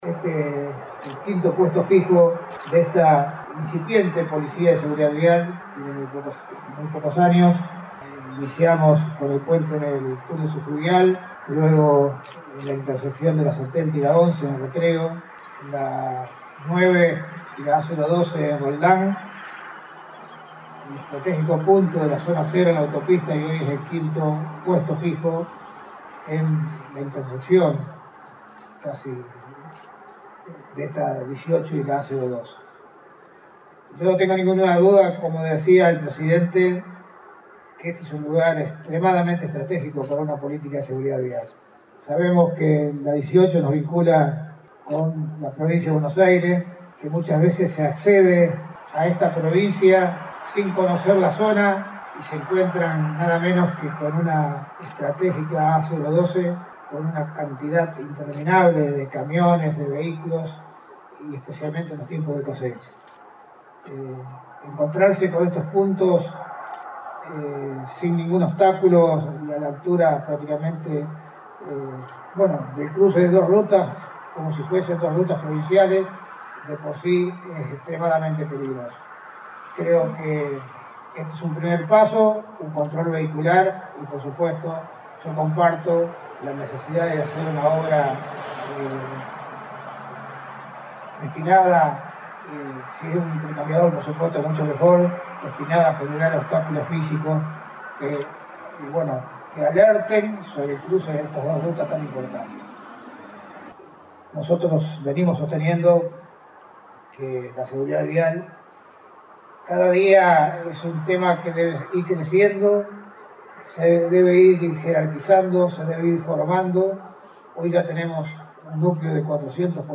El ministro de Seguridad, Raúl Lamberto, encabezó el acto de inauguración de un nuevo puesto de control fijo de la Policía de Seguridad Vial, en el cruce de la Ruta Nacional AO12 y Ruta Provincial Nº 18, jurisdicción Alvear.